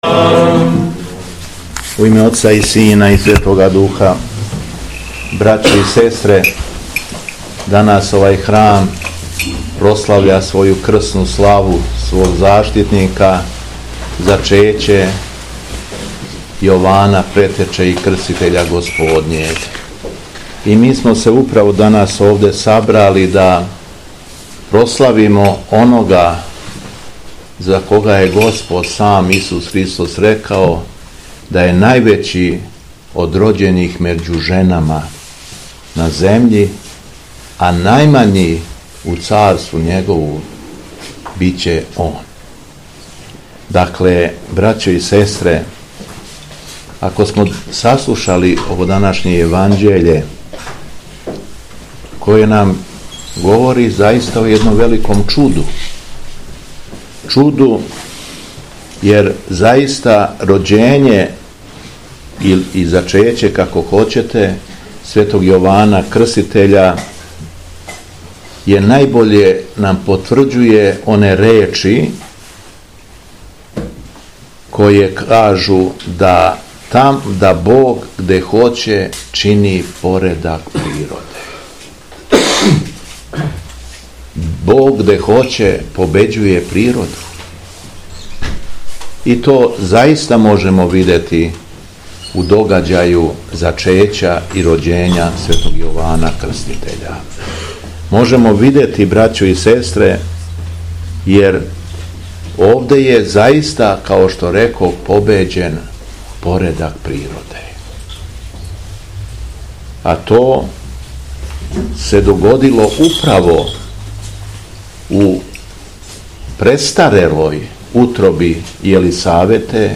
СВЕТА ЛИТУРГИЈА У ХРАМУ ЗАЧЕЋА СВЕТОГ ЈОВАНА ПРЕТЕЧЕ И КРСТИТЕЉА У ПОСКУРИЦАМА
Беседа Његовог Високопреосвештенства Митрополита шумадијског г. Јована